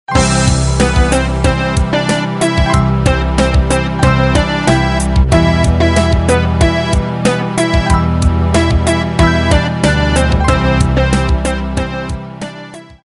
Послушать пример мелодии
• Пример мелодии содержит искажения (писк).